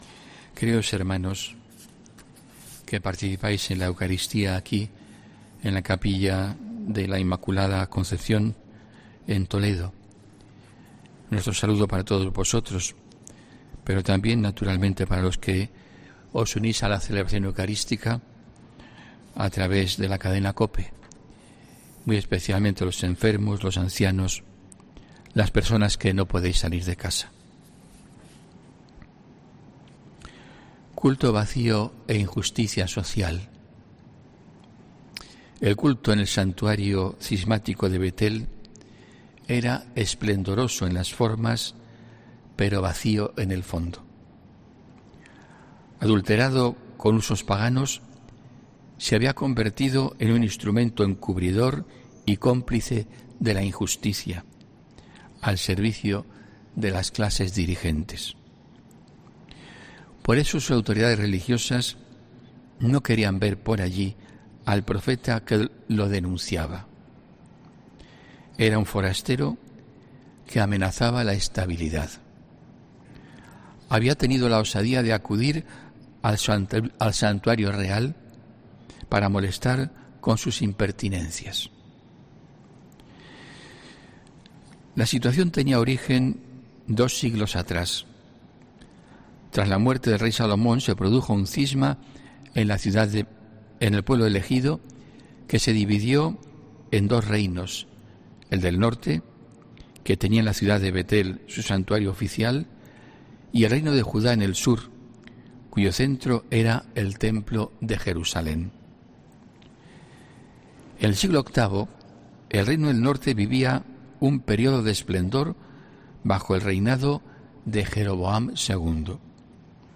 HOMILÍA 11 JULIO 2021